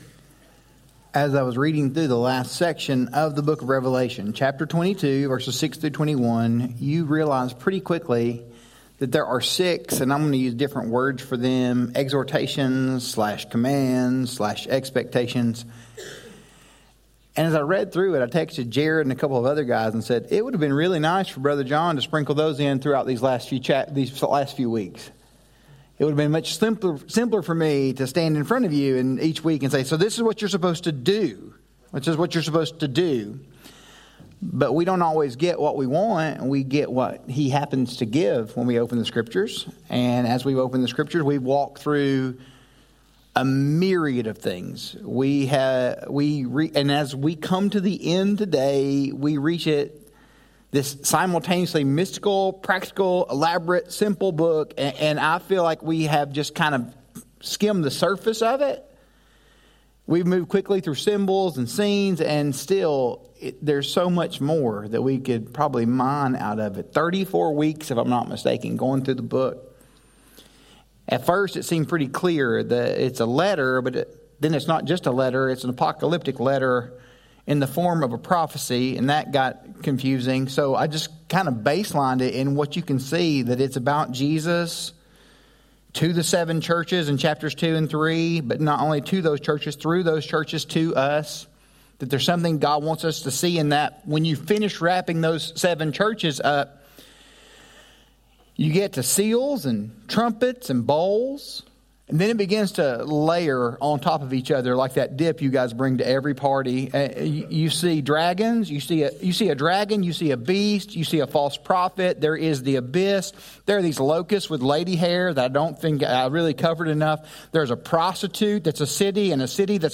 Sermon audio from Grace Bible Church in Clute, TX